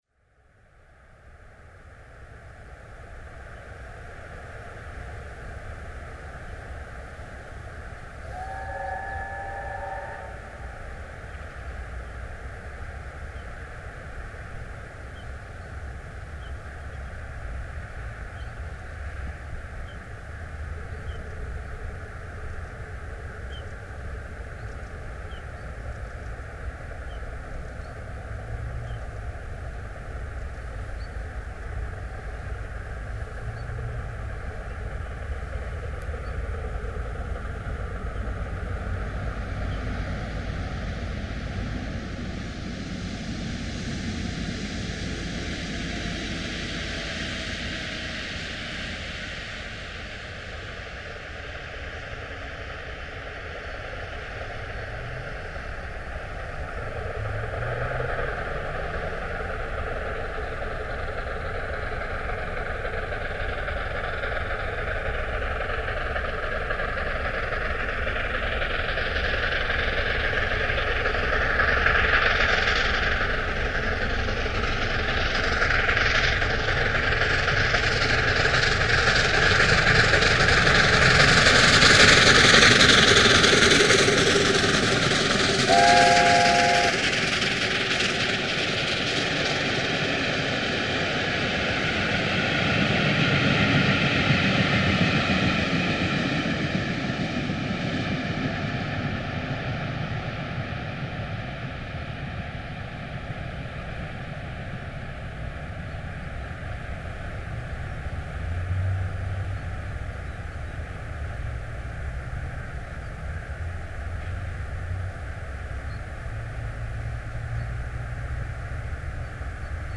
We  are situated next to the East Coast Main Line at Challenors Whin, just south of York.  First we hear the  Gresley 3 chime whistle in the distance before the sound of an A4 accelerating to line speed reaches the  microphone.  Given we are next to a busy 4 track mainline (and also near a busy road!), its no surprise that  a service train briefly interrupts the sound of steam before the A4 races by us on its way to Kings Cross.